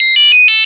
on entend un son)
OK_Beep.wav